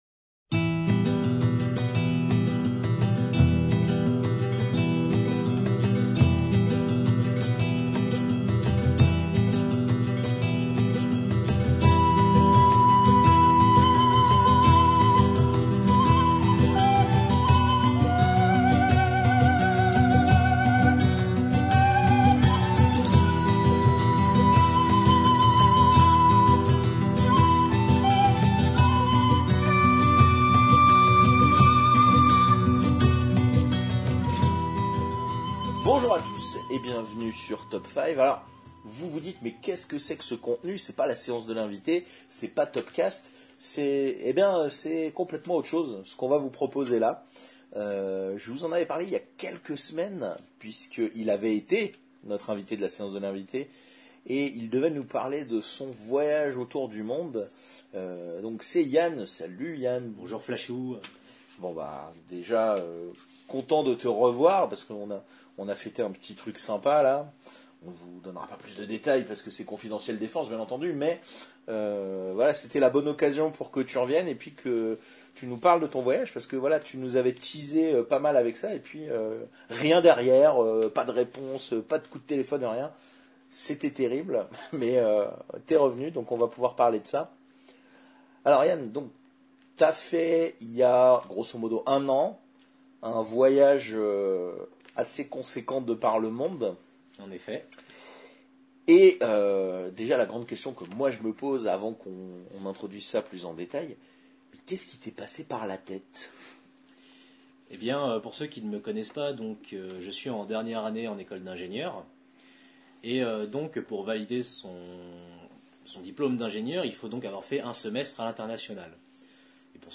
Vu la densité de cette interview, nous allons vous la proposer en deux parties. La première nous conduira de Tokyo jusqu’à Shanghai et offrira a notre globe trotter bien plus qu’il ne l’espérait…